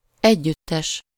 Ääntäminen
France (Paris): IPA: [ɑ̃.sɑ̃bl]